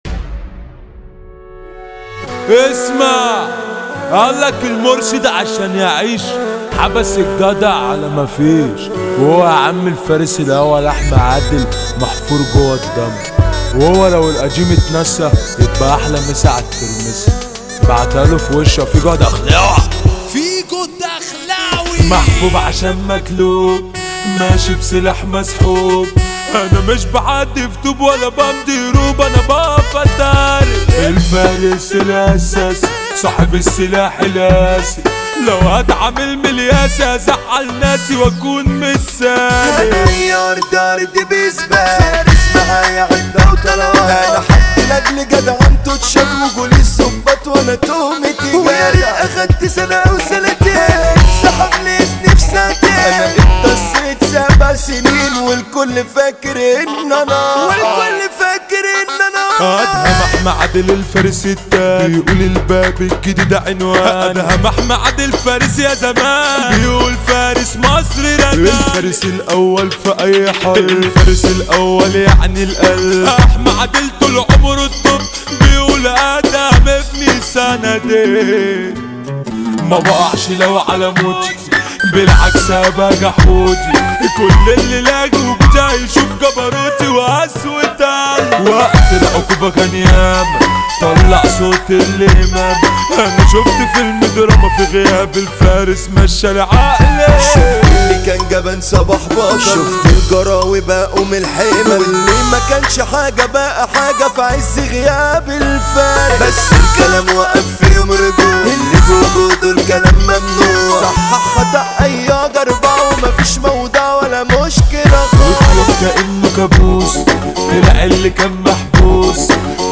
مهرجانات